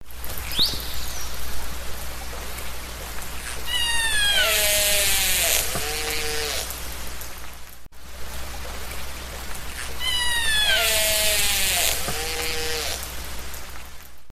جلوه های صوتی
دانلود صدای دلفین 13 از ساعد نیوز با لینک مستقیم و کیفیت بالا